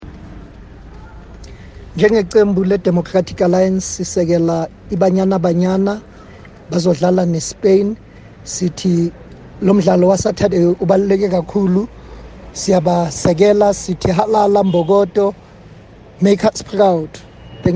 Find attached soundbites in English and
Zulu from DA Shadow Minister of Sports, Arts & Culture, Tsepo Mhlongo MP.